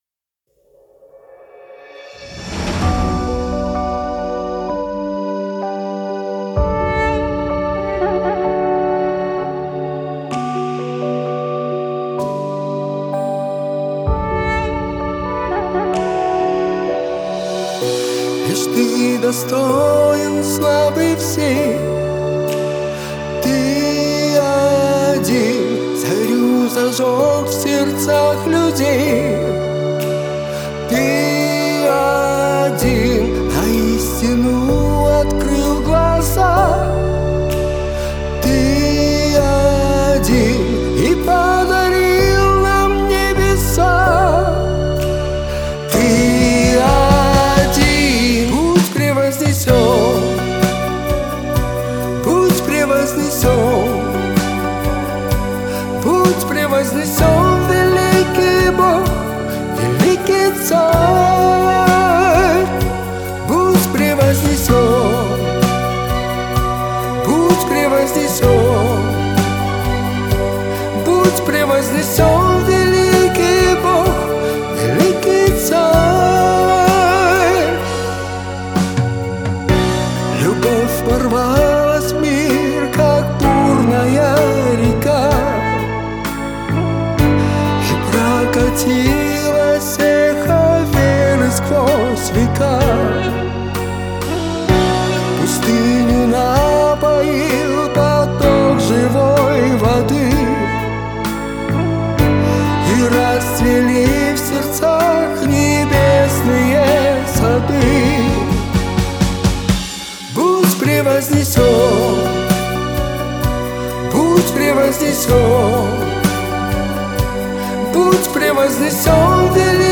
155 просмотров 199 прослушиваний 23 скачивания BPM: 128